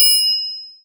DDW3 TRNGL 1.wav